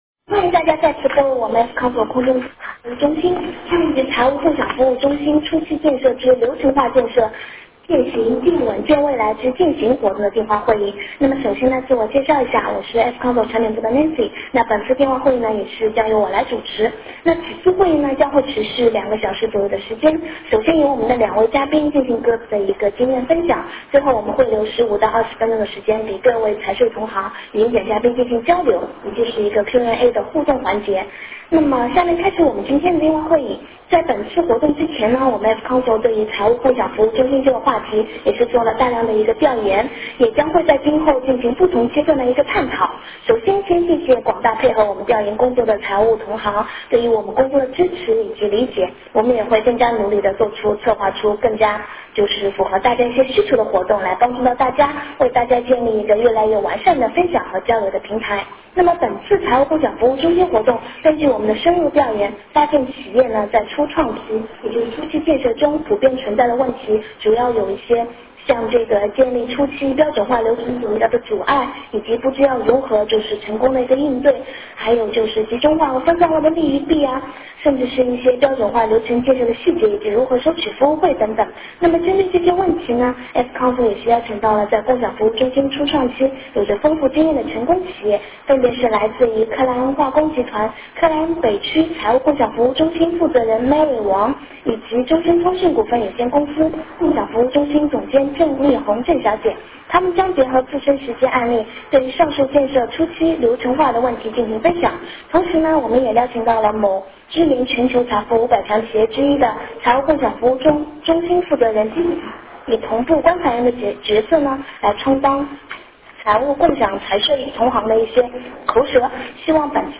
F-Council空中电话会议中心2010年企业财务共享服务中心系列一